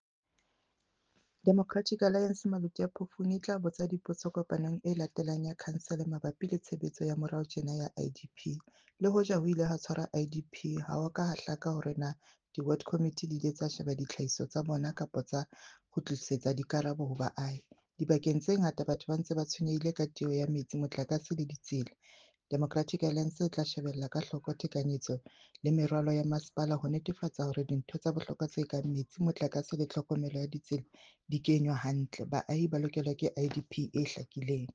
Sesotho soundbites by Cllr Ana Motaung and